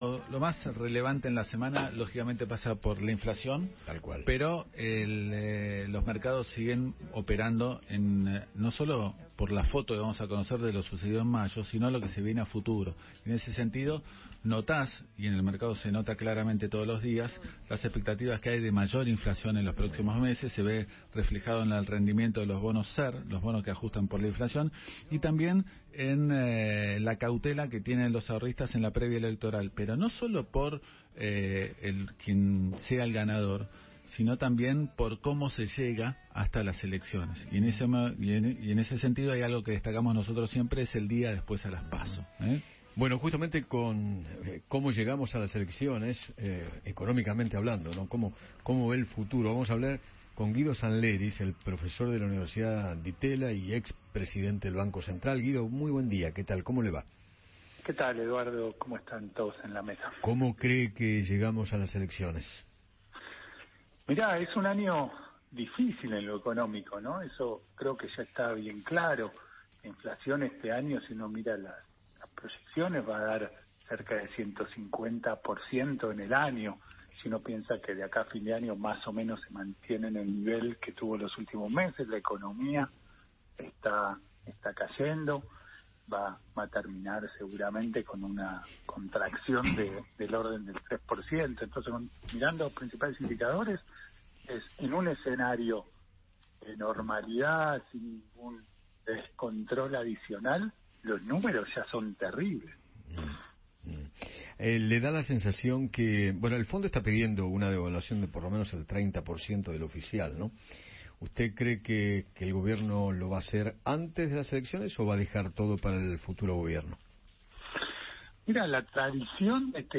Guido Sandleris, Expresidente del Banco Central, dialogó con Eduardo Feinmann sobre el panorama económico y analizó las expectativas de inflación del mes de mayo.